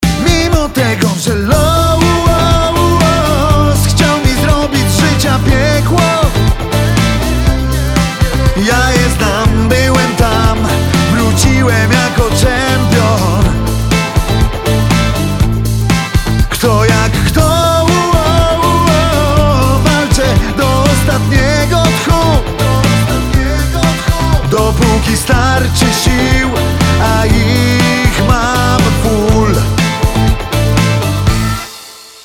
fragment refrenu